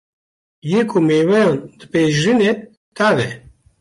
Uitgesproken als (IPA)
/tɑːv/